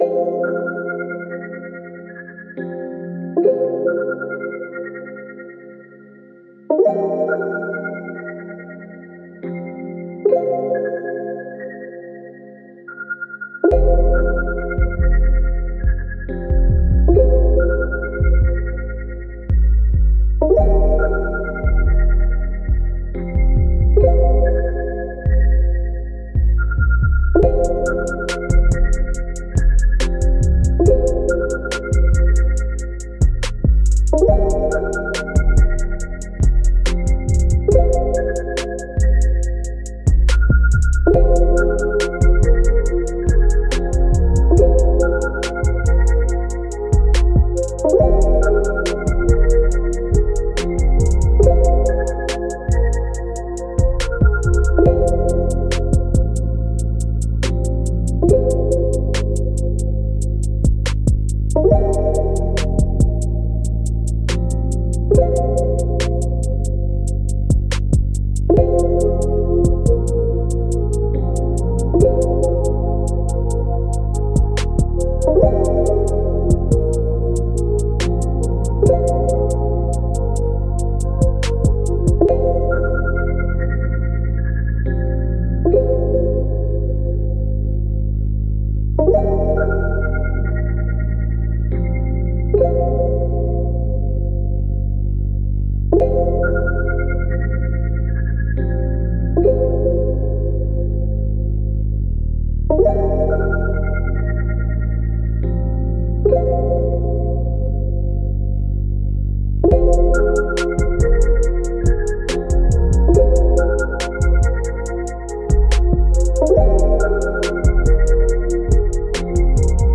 R&B – Trapsoul – Dreamlike Type Beat
Key: Fm
140 BPM